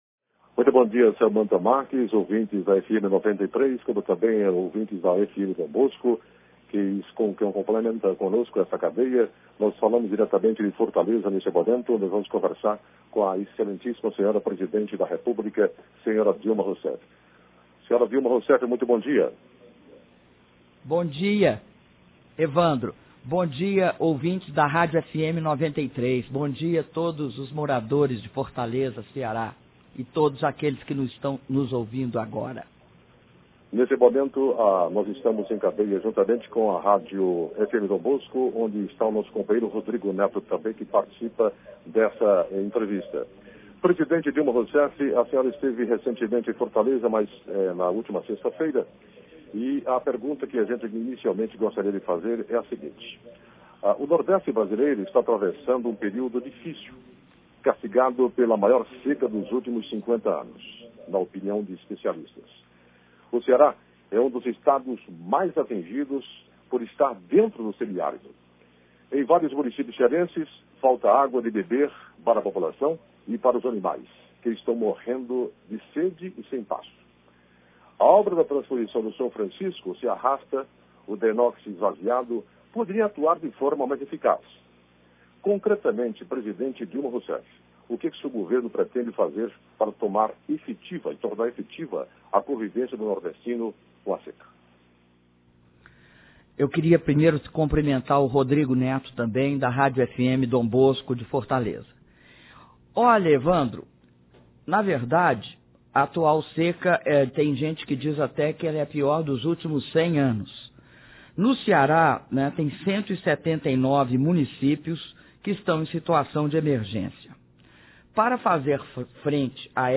Áudio da entrevista concedida pela Presidenta da República, Dilma Rousseff, para as rádios FM Dom Bosco e FM 93, do Ceará - Palácio da Alvorada/DF